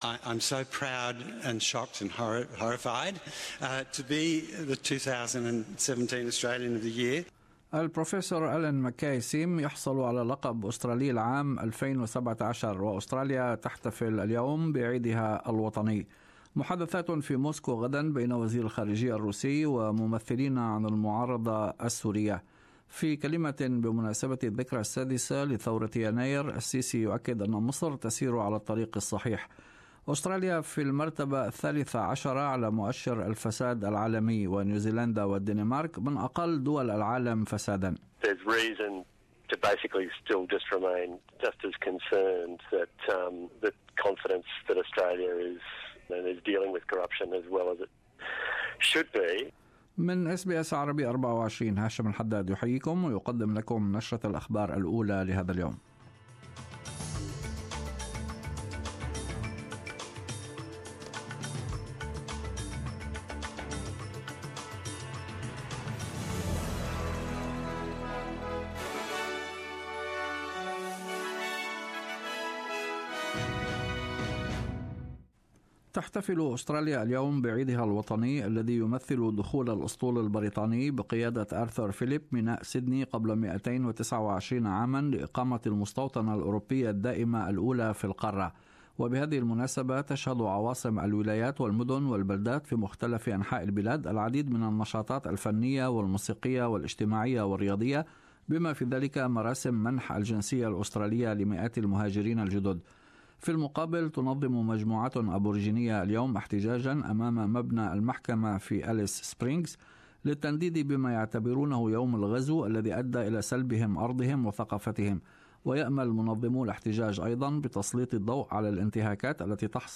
Arabic News Bulliten 26-1-17